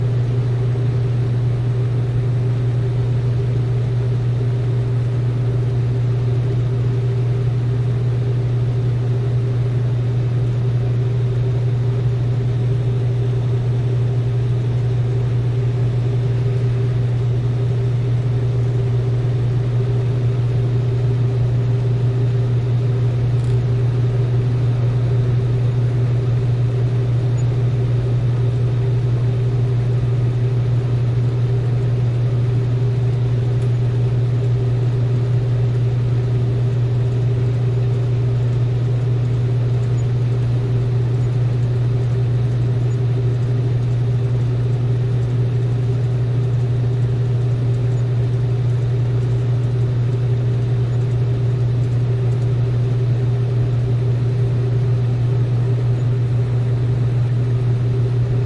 自助洗衣店 " 自助洗衣店的洗衣机 洗衣机的隆隆声2
描述：洗衣店洗衣机rumble2.flac
Tag: 洗涤 洗衣店 垫圈 隆隆声